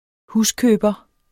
Udtale [ ˈhuskøːbʌ ]